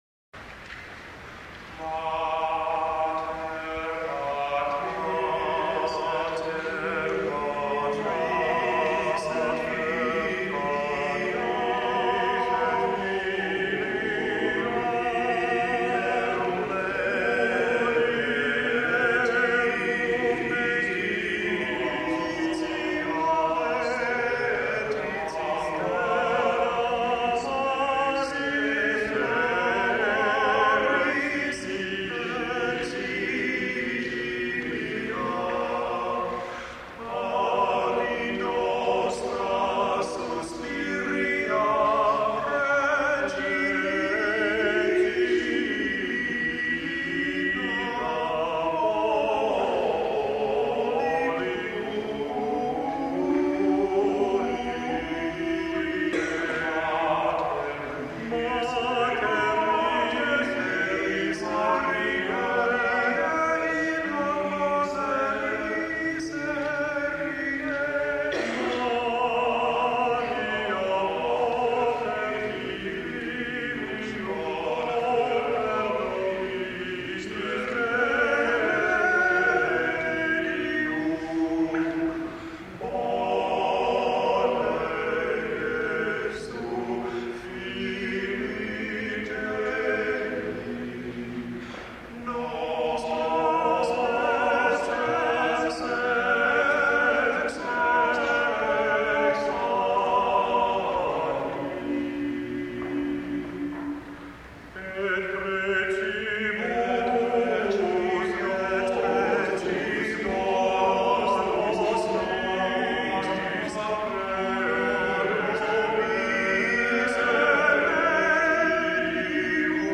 A Marian antiphon motet for three male voices . . . alternating points of imitation . . . then homorhythm . . . and a short triple meter section to close.
tenors
bass